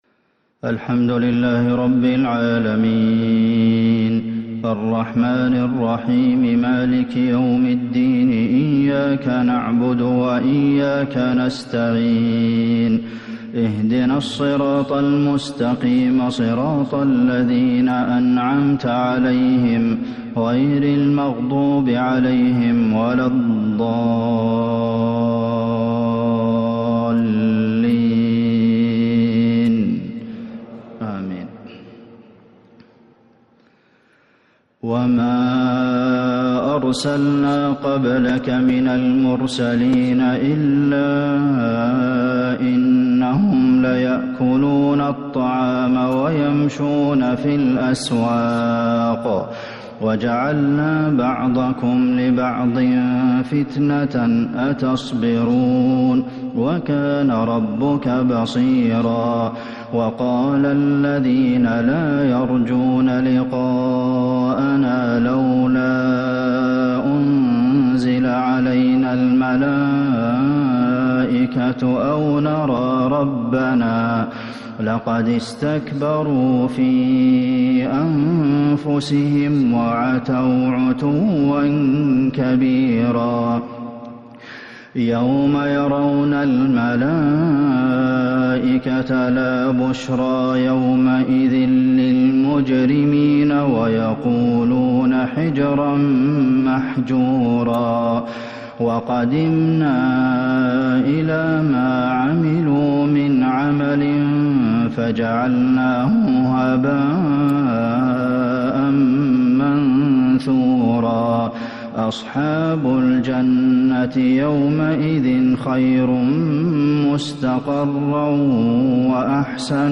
عشاء الخميس 9-2-1443هـ من سورة الفرقان | Isha prayer from Surah Al-Furqān 16/9/2021 > 1443 🕌 > الفروض - تلاوات الحرمين